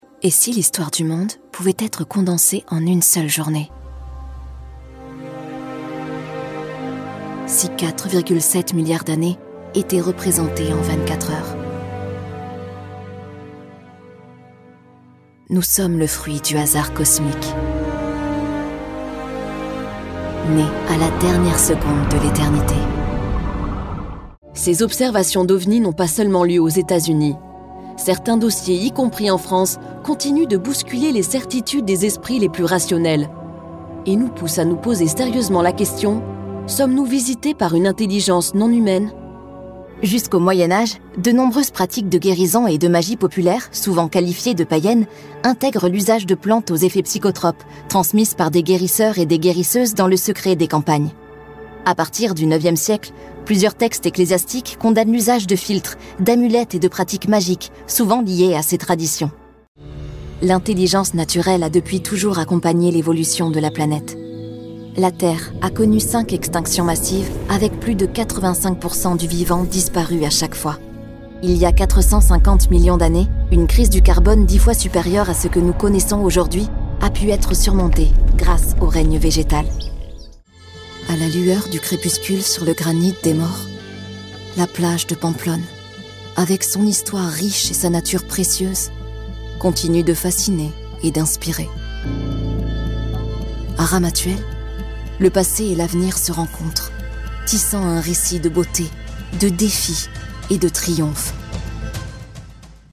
Naturelle, Enjouée, Amicale, Jeune, Douce
Guide audio